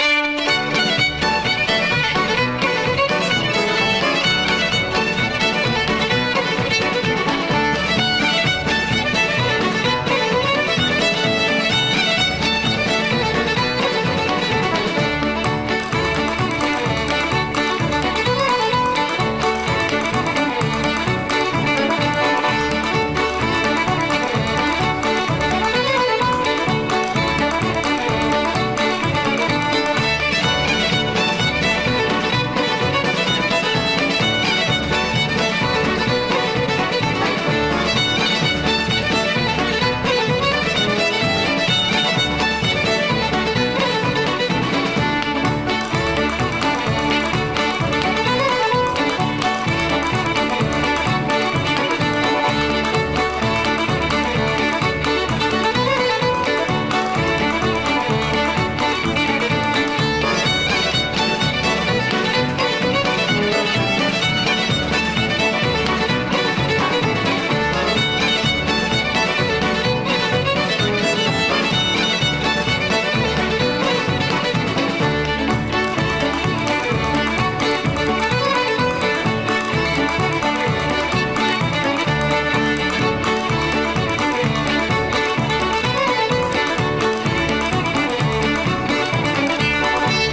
(129bpm)